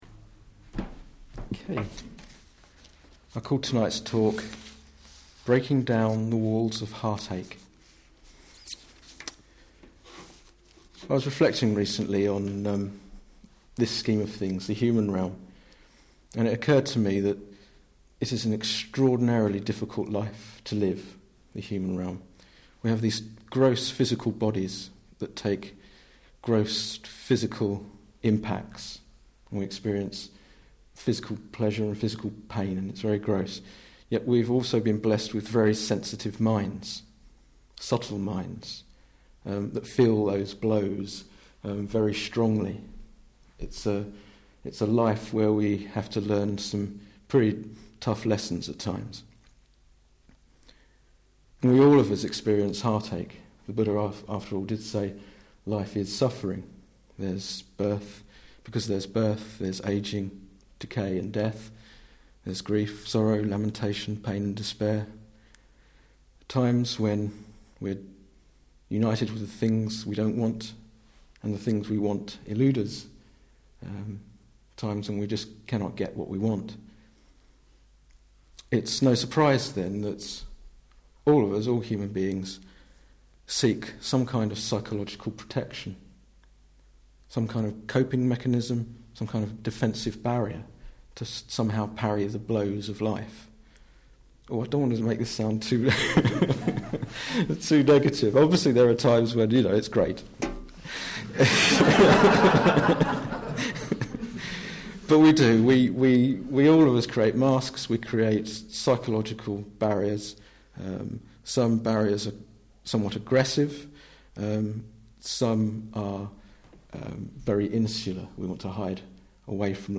This talk was given in August 2008.